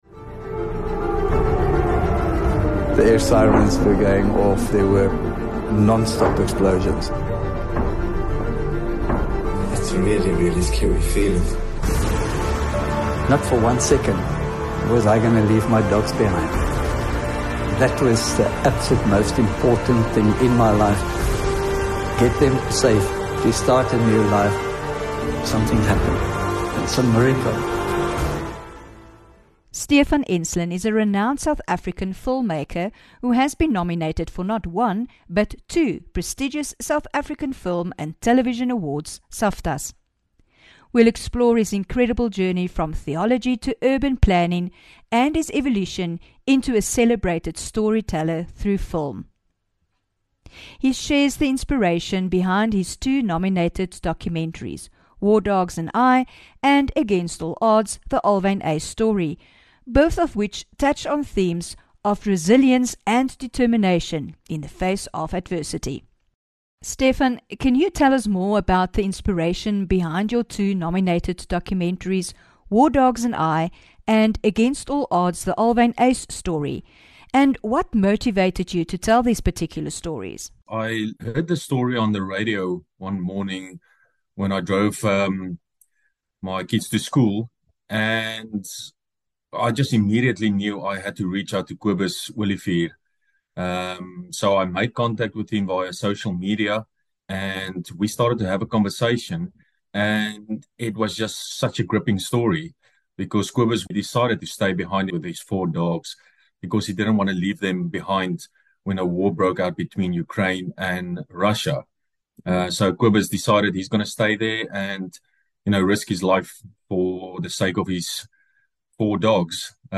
22 Oct INTERVIEW